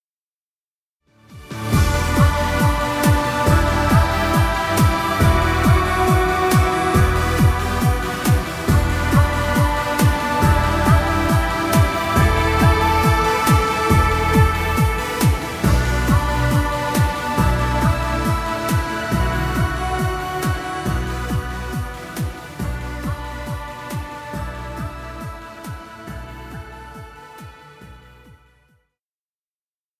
Dance music.. Background music Royalty Free.